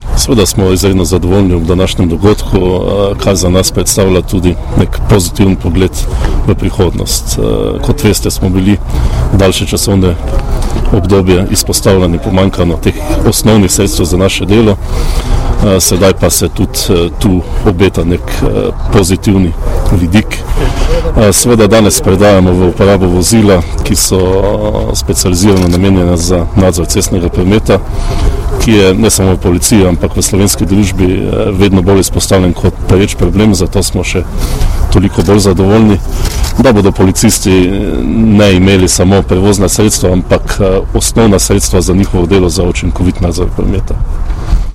Vozni park slovenske policije je bogatejši za 30 novih motornih koles, osem službenih vozil za oglede krajev prometnih nesreč ter pet civilnih vozil z vgrajenim videonadzornim sistemom Provida. Slovesni prevzem je bil danes, 15. novembra 2016, udeležil pa se ga je tudi generalni direktor policije Marjan Fank.
Zvočni posnetek izjave generalnega direktorja policije Marjana Fanka (mp3)